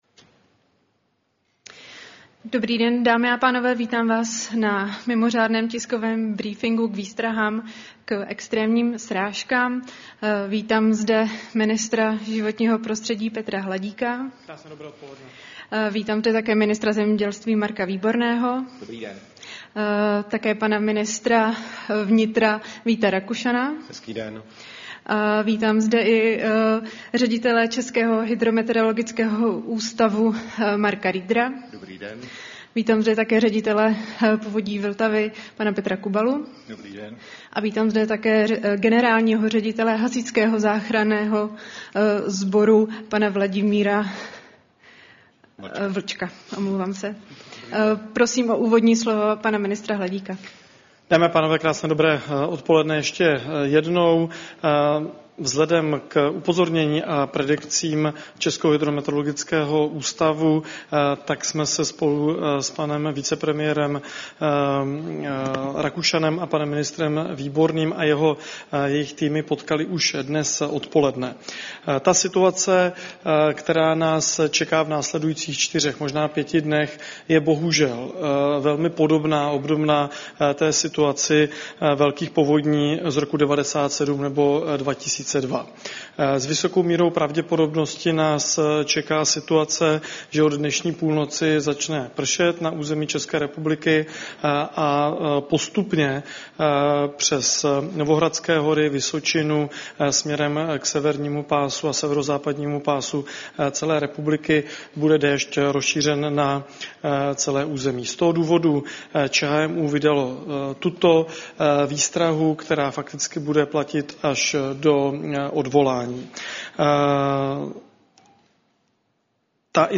Tisková konference k hrozbě extrémních srážek a povodní, 11. září 2024